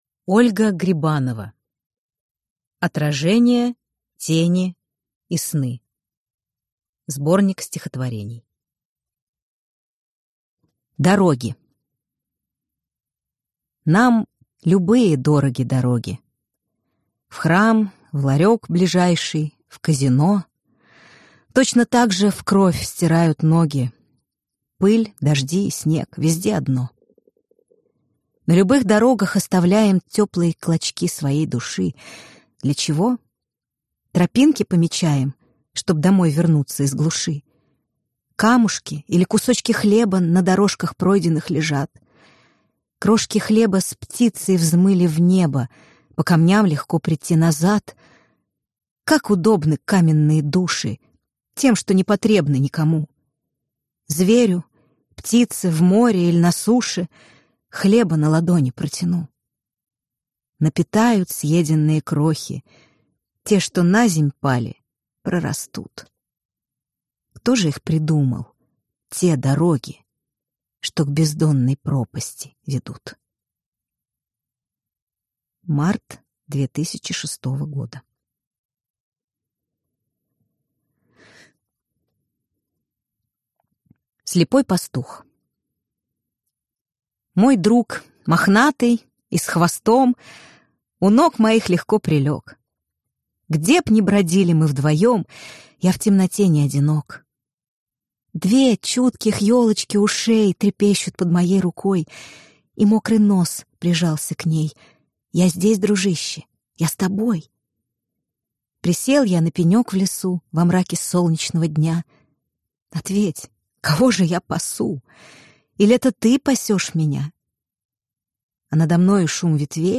Аудиокнига Отражения, тени и сны | Библиотека аудиокниг